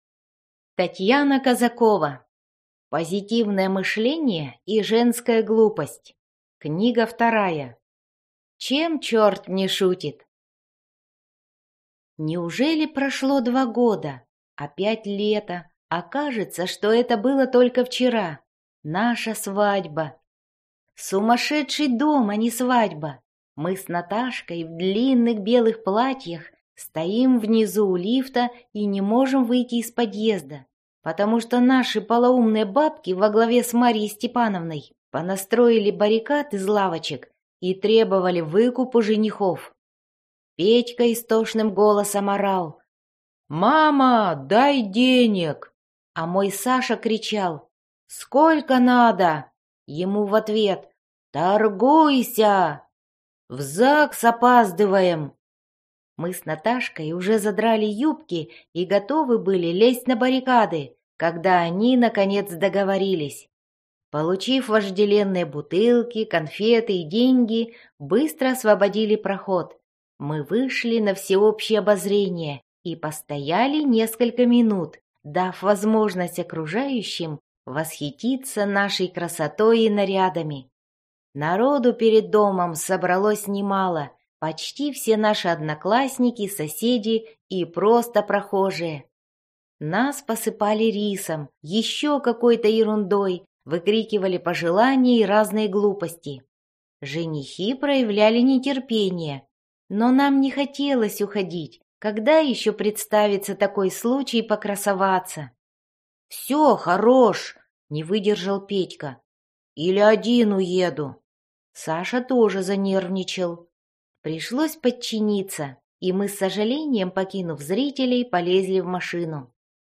Прослушать фрагмент аудиокниги Чем черт не шутит Татьяна Казакова Детективы Современные детективы Современная Проза Произведений: 2 Скачать бесплатно книгу Скачать в MP3 Вы скачиваете фрагмент книги, предоставленный издательством